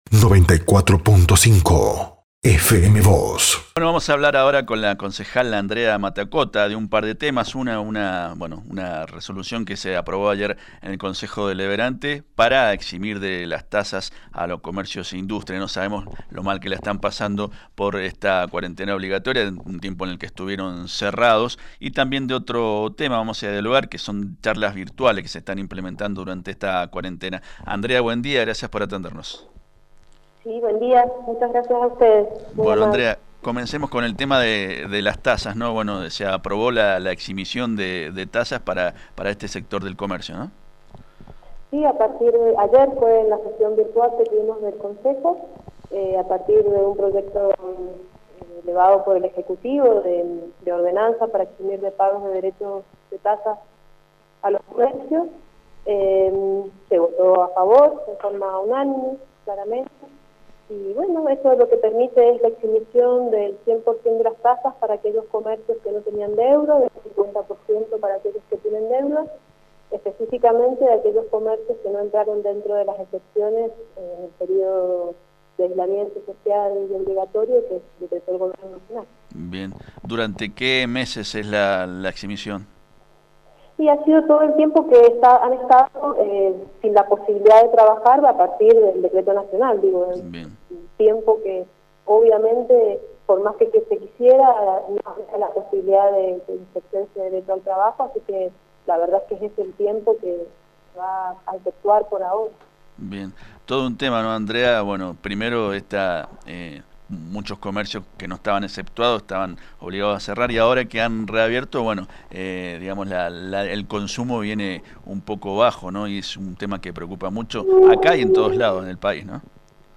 Diario San Rafael y FM Vos (94.5) hablaron al respecto con la concejal Andrea Mattacota, quien además se refirió a un interesante programa de charlas online que se está desarrollando.